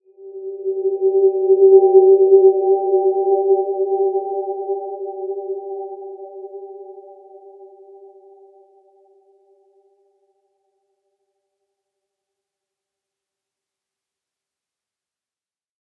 Dreamy-Fifths-G4-mf.wav